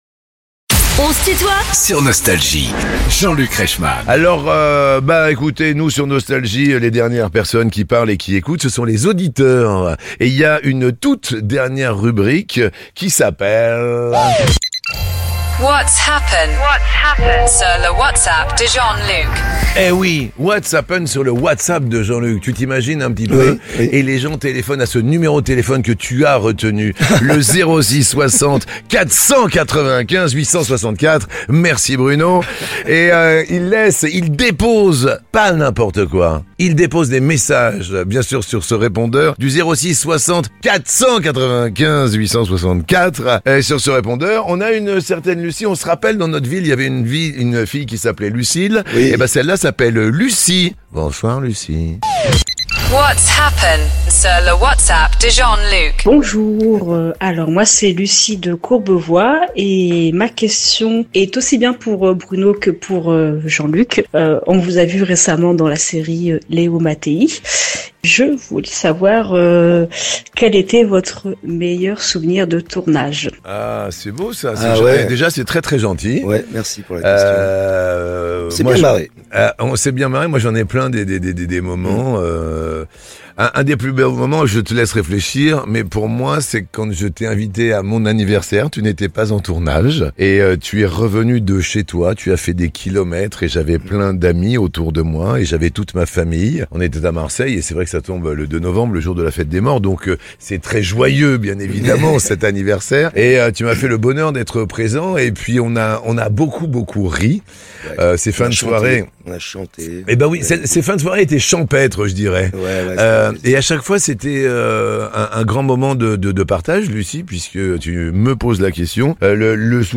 Benabar est l'invité de "On se tutoie ?..." avec Jean-Luc Reichmann